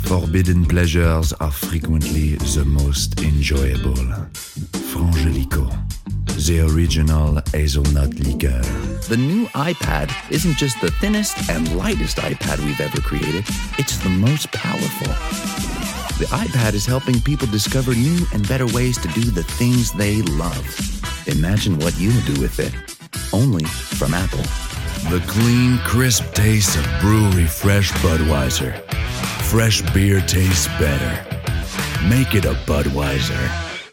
French-Canadian, Male, Home Studio, 30s-40s